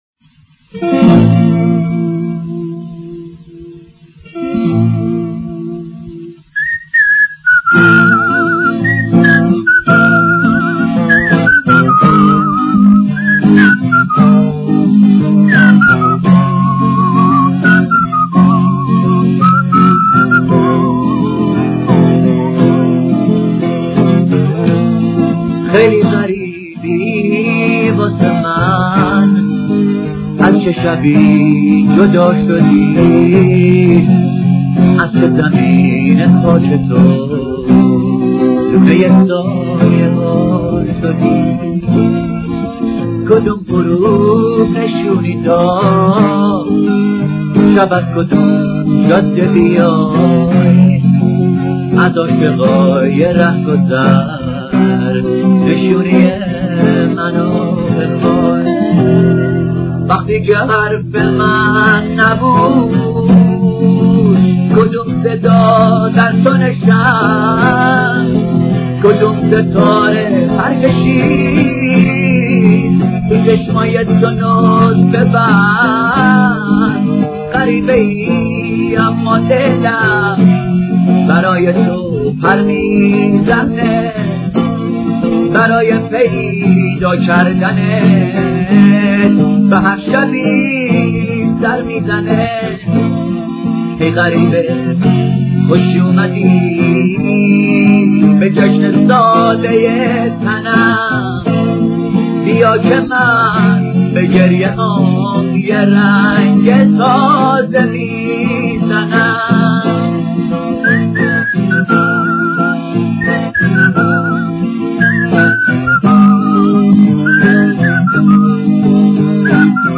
آهنگ قدیمی آهنگ غمگین